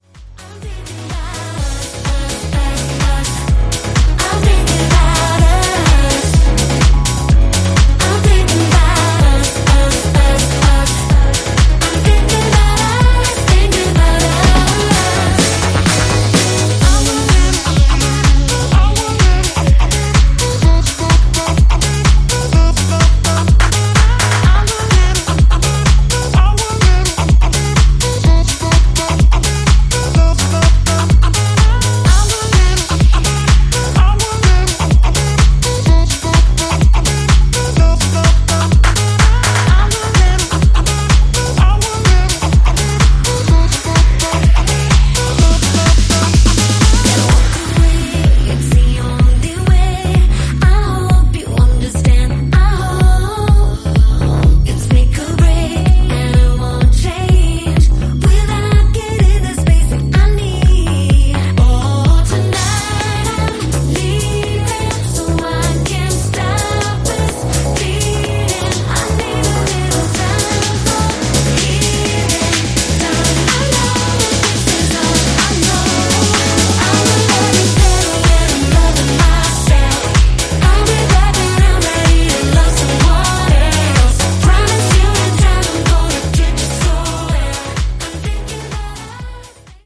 ジャンル(スタイル) POP / HOUSE